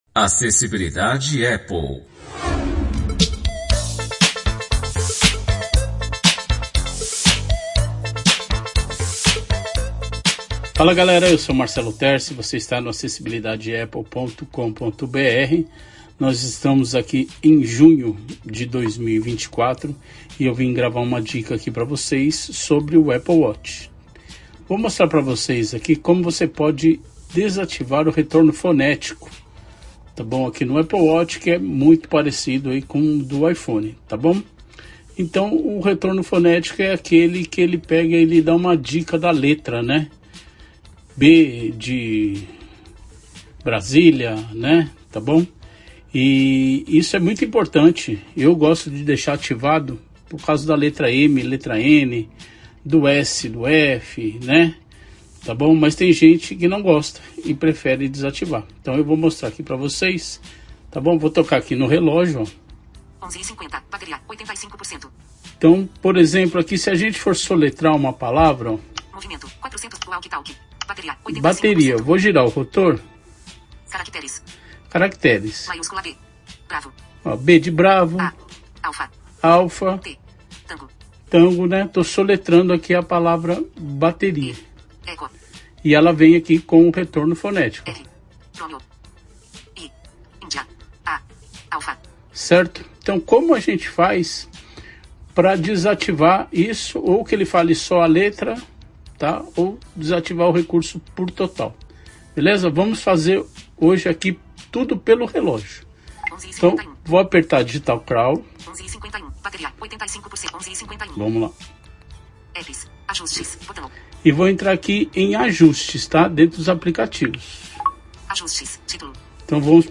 Áudio tutorial